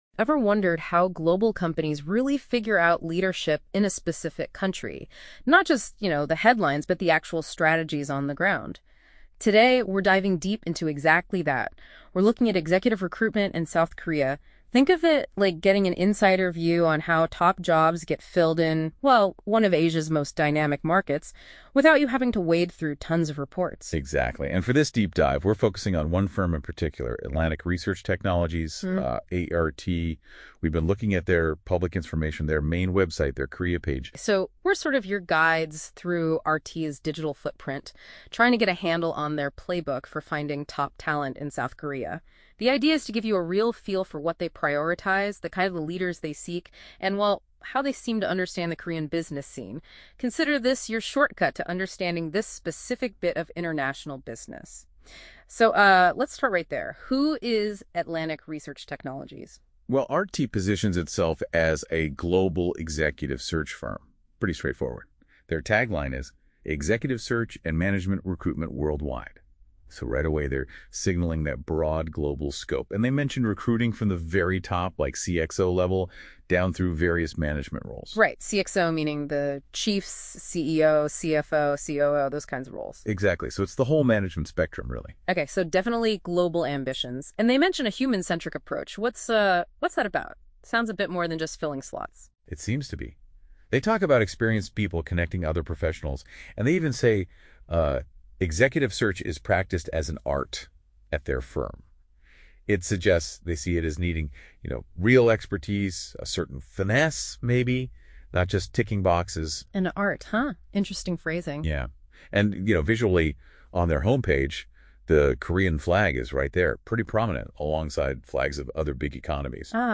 • Please download a podcast discussing ART's executive search and management recruitment in Korea (by Google Gemini AI):